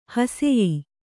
♪ haseyī